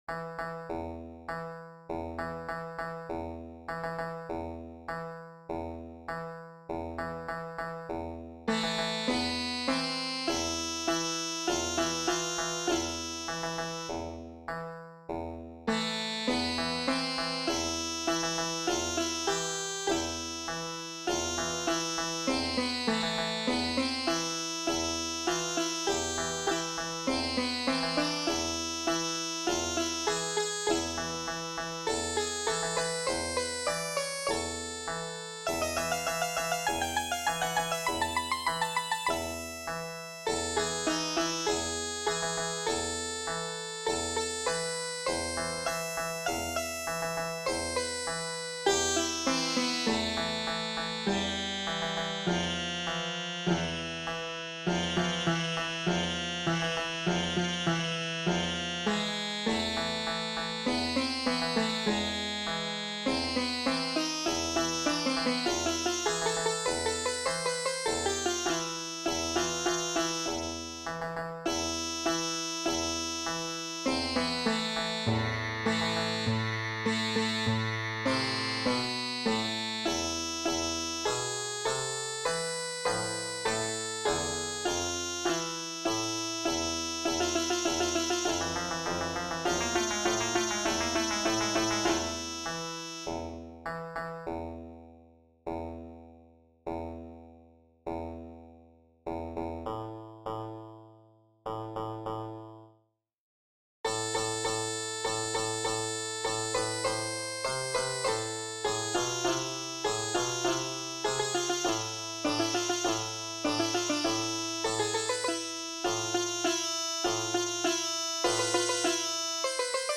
I include a link to the dance by Sheba, then Srat, at the moment Indalecio, then Damil, meets her.